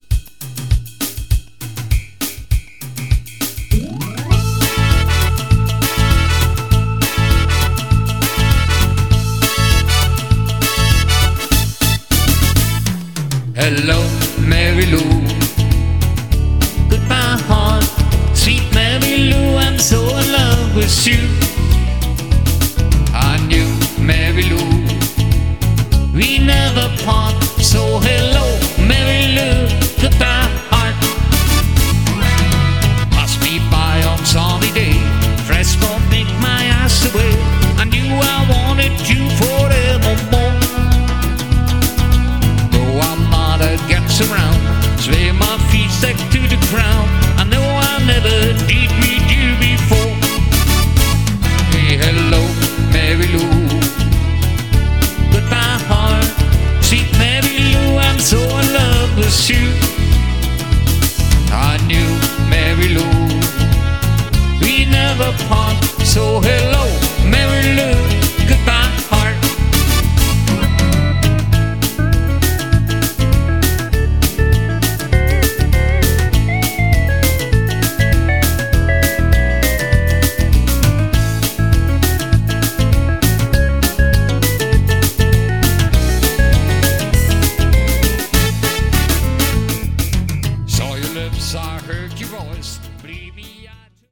Alleinunterhalter mit Keyboard & Gesang
KEYBOARD & GESANG